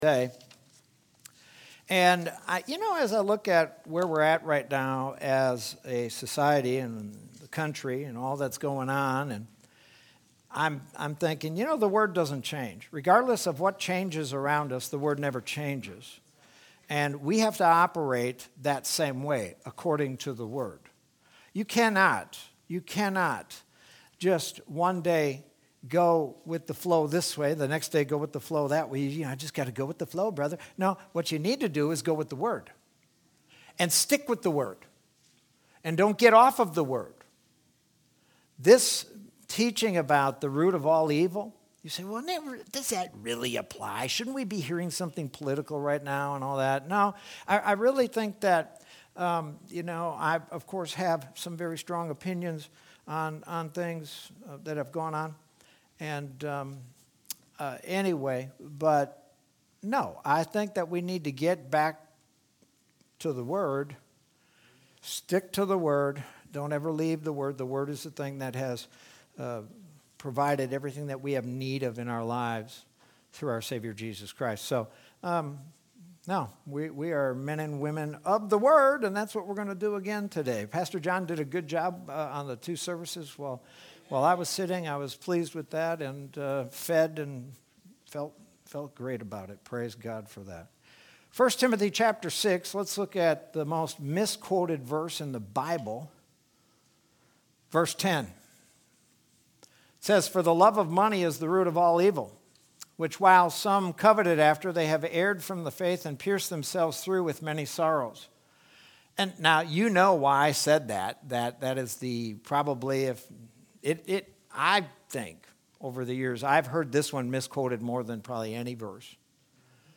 Sermon from February 7th, 2021.